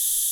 steam.wav